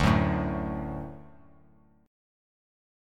D Chord
Listen to D strummed